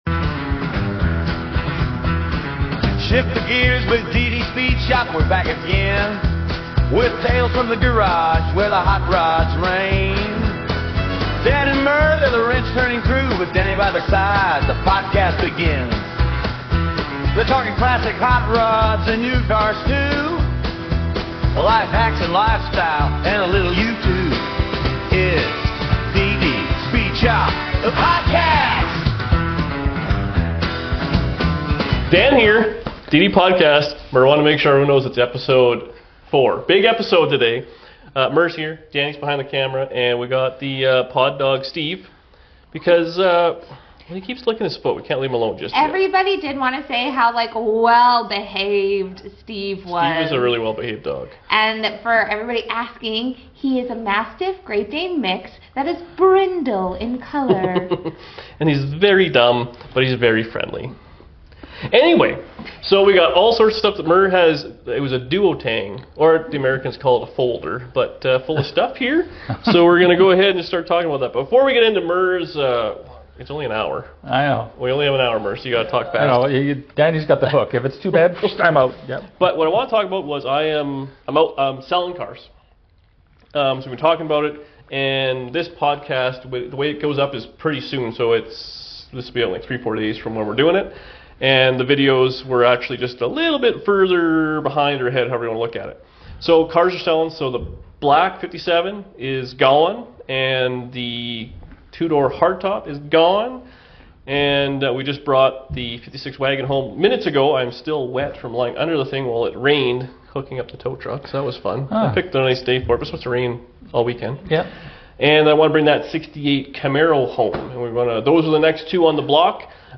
Today we chat about selling off a bunch of cars in the fleet, the tools you MUST have and can openers? It was a pretty eventful conversation.